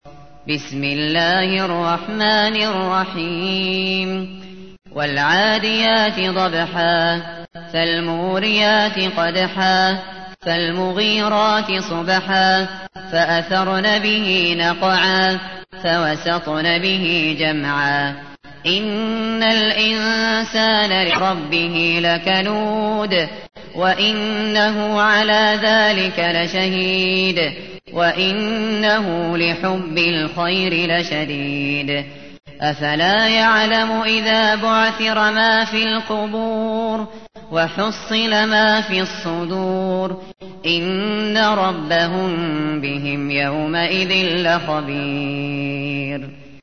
تحميل : 100. سورة العاديات / القارئ الشاطري / القرآن الكريم / موقع يا حسين